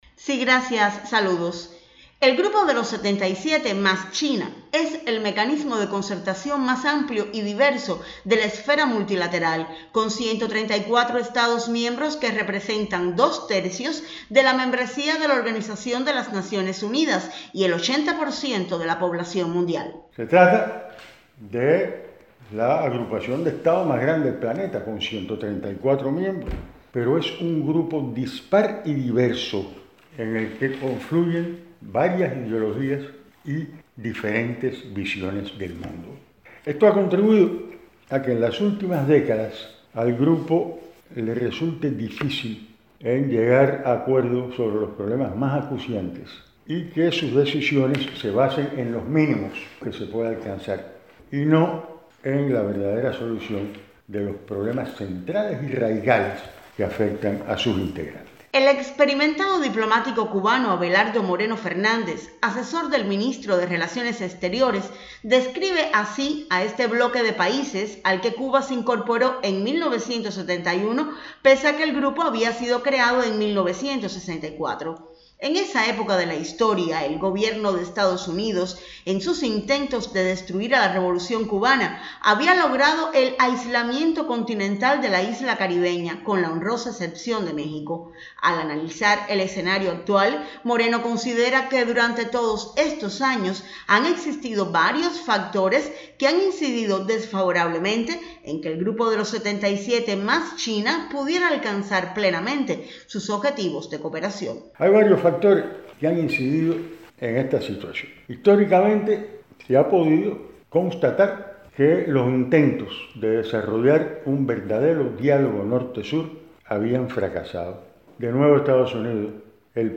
abelardo_entrevista.mp3